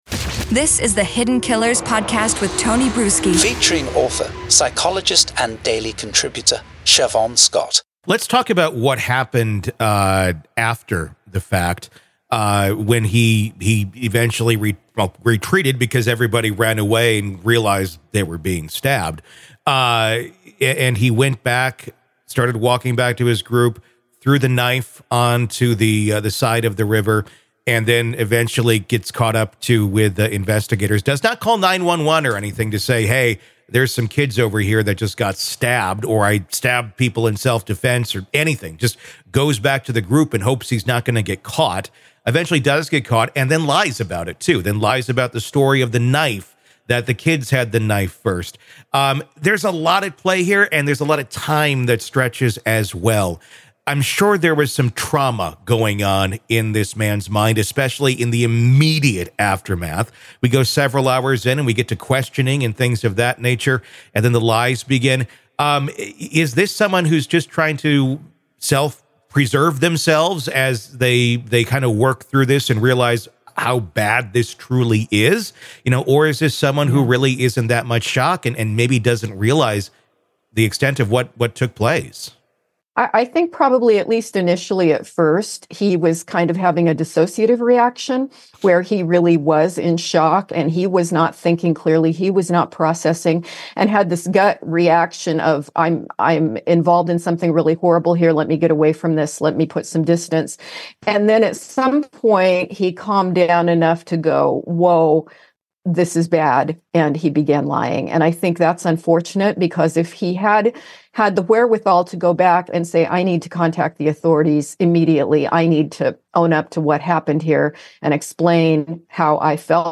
The conversation also explores broader societal issues, questioning why such violent group behaviors seem increasingly prevalent among today’s youth and whether societal changes or greater media visibility play roles in this perception. Main Points from the Conversation Initial Reaction to Violence